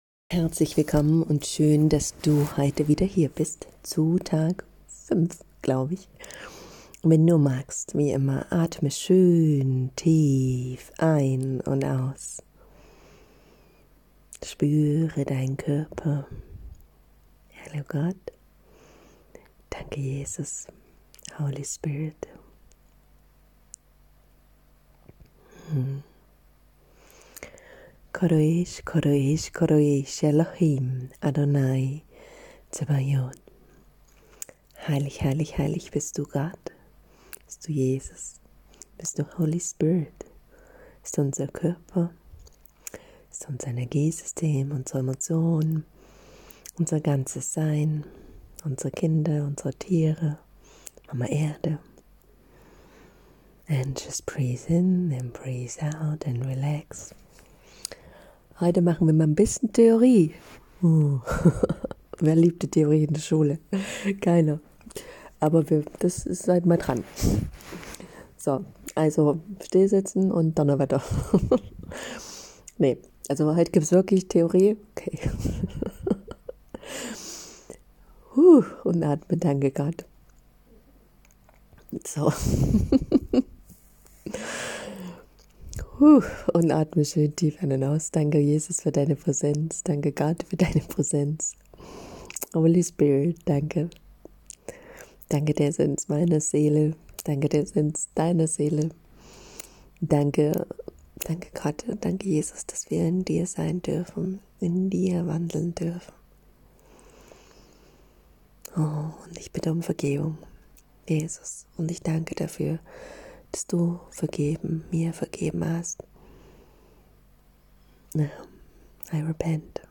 Wie "manifestiert" man biblisch, mit Gottes Hilfe? Höre gerne in diese Folge hinein, die aus dem April-Kurs ist, zu dem auch du herzlich eingeladen bist.
21_-_Bibelstunde_-_Manifestation(1).m4a